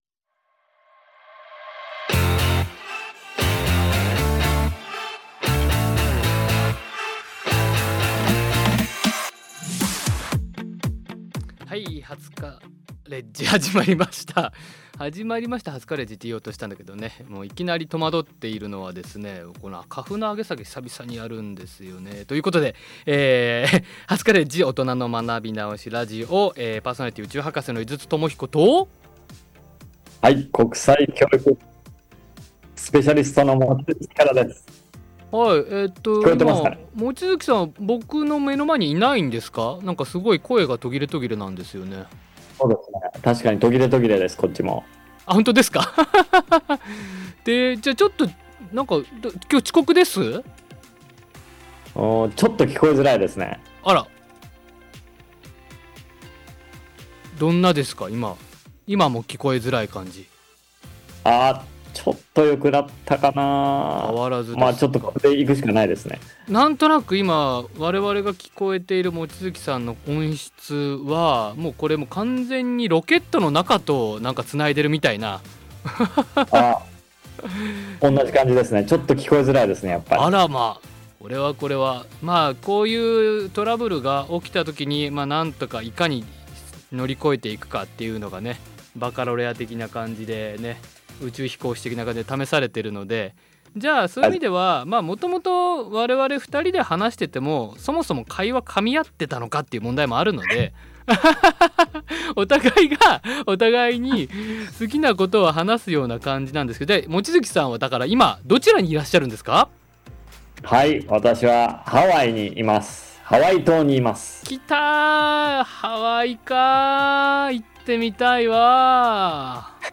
今回はスペシャル会で、ハワイと廿日市のスタジオを生中継！ 廿日市市はハワイ州ハワイ郡と姉妹都市提携を結んでいます。